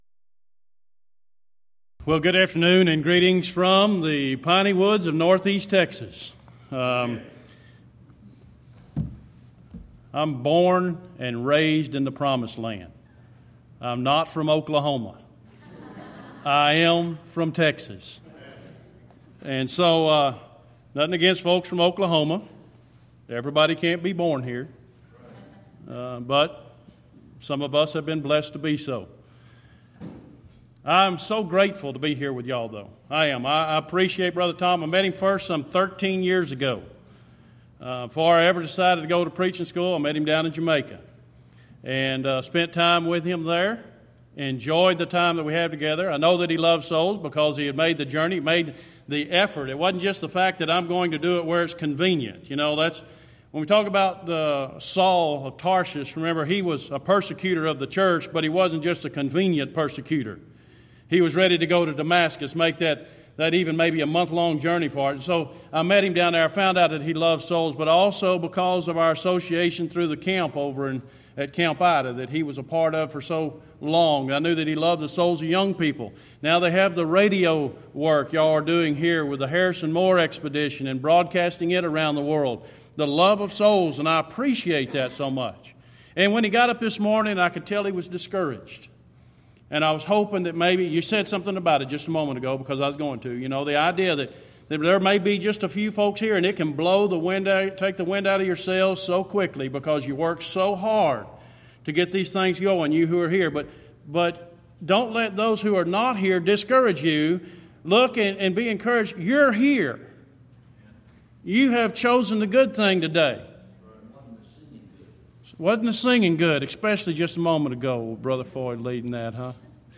Event: 4th Annual Back to the Bible Lectures Theme/Title: The I Am's of Jesus